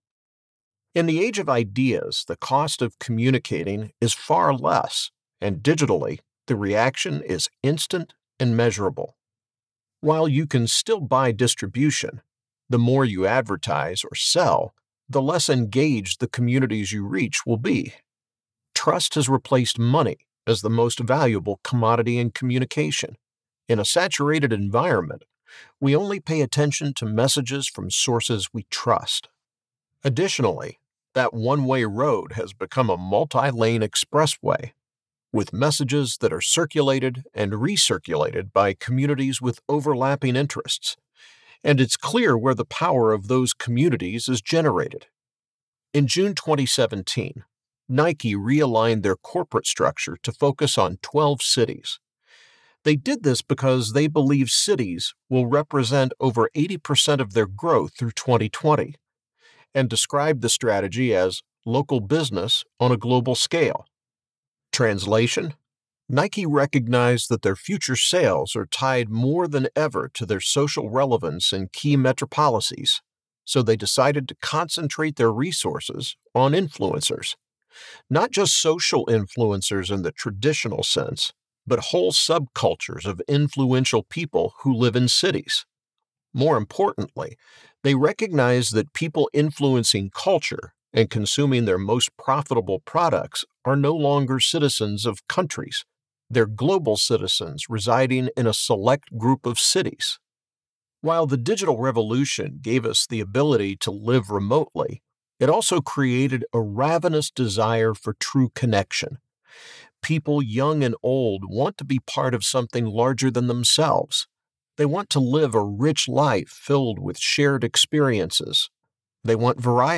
Male
English (North American), English (Neutral - Mid Trans Atlantic)
Adult (30-50)
A storyteller by nature, he can deliver a wide variety of reads that can be playful and fun or serious and trustworthy.
Audiobooks
Non-Fiction - Business
Words that describe my voice are guy next door, professional, authoritative.